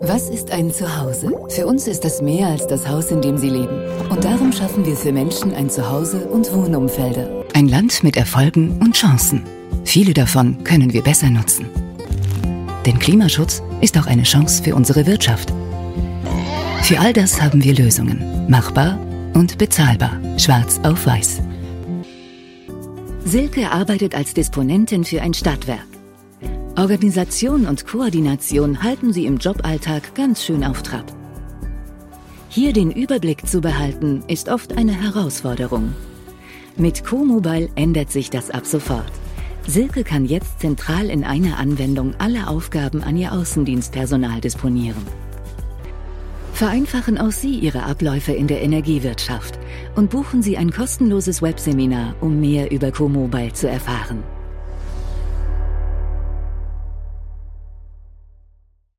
Native Voice Samples
Explainer Videos
Neumann U87 mic, RME UC Audio interface, professionel speaker booth, Protools, SesssionLinkPro, Source connect now, Teams, Zoom